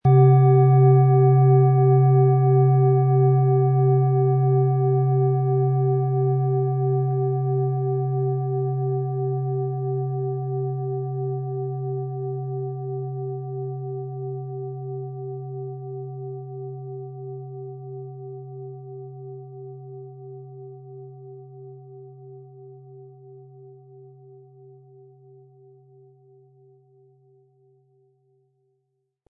Planetenton
Diese tibetanische Biorhythmus Körper Planetenschale kommt aus einer kleinen und feinen Manufaktur in Indien.
Den passenden Schlegel erhalten Sie kostenfrei mitgeliefert, der Schlägel lässt die Schale voll und wohltuend erklingen.
MaterialBronze